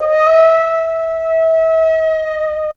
ZG FLUTE 3.wav